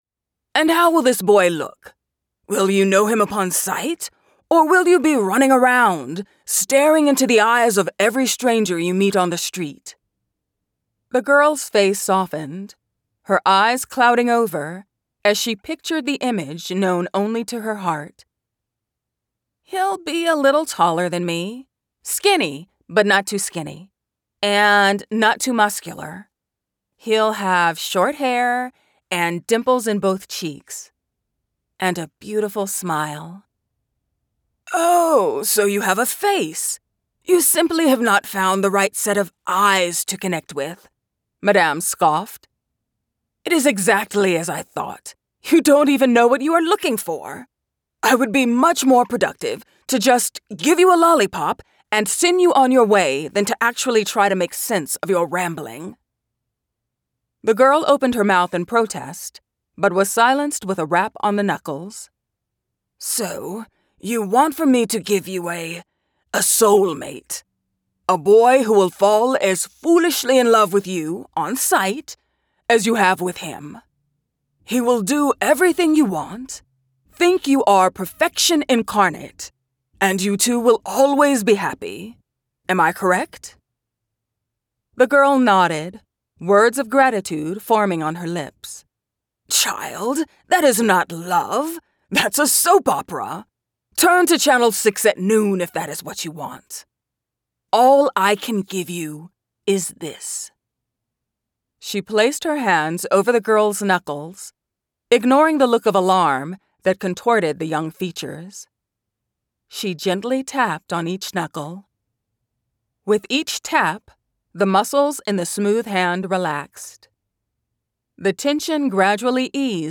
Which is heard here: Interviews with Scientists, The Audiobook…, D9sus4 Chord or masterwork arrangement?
The Audiobook…